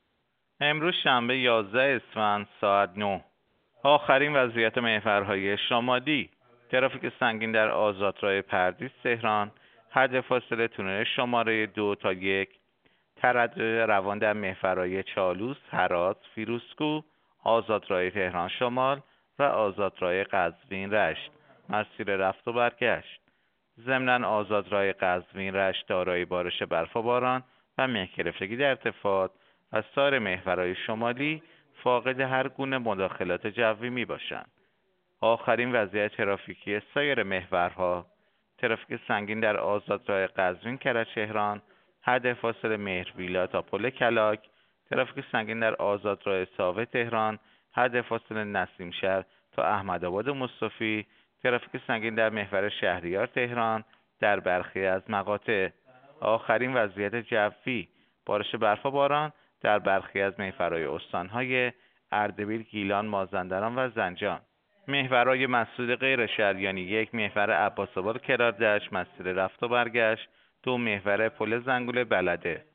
گزارش رادیو اینترنتی از آخرین وضعیت ترافیکی جاده‌ها ساعت ۹ یازدهم اسفند؛